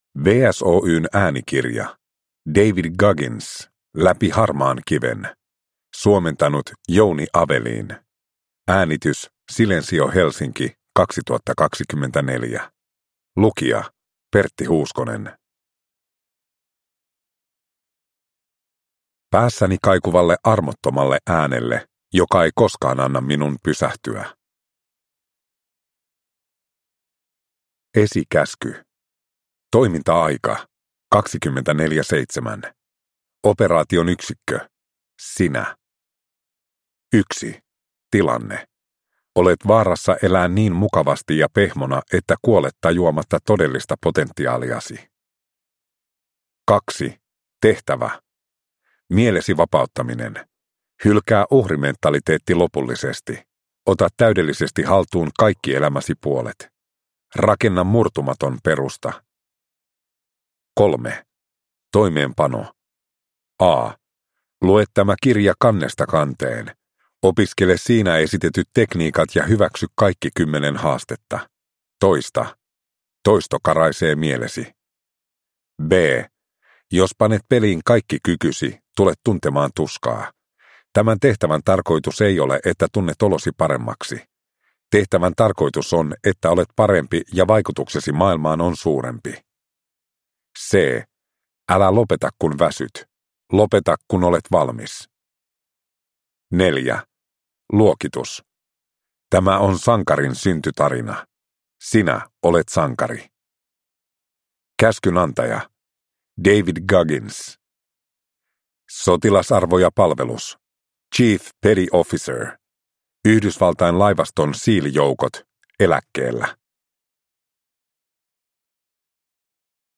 Läpi harmaan kiven – Ljudbok